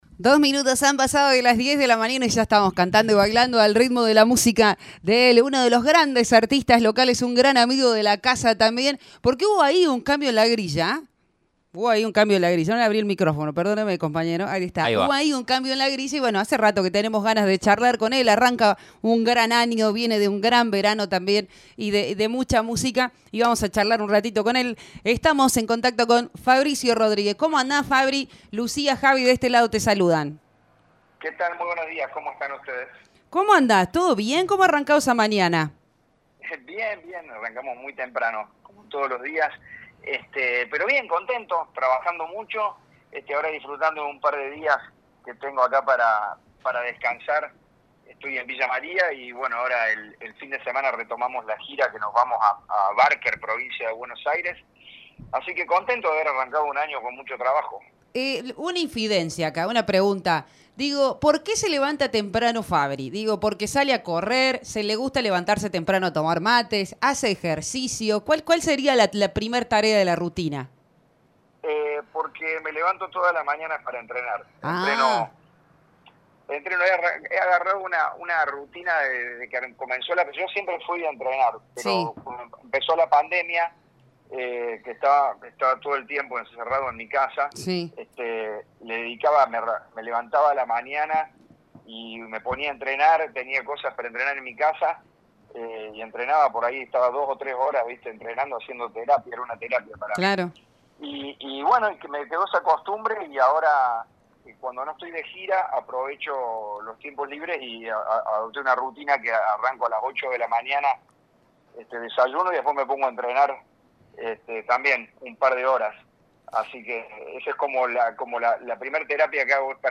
conversar con la 106.5